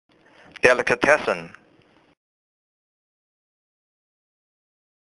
老美都是這麼唸！
這才是道地的美式發音喔！
注意重音的位置。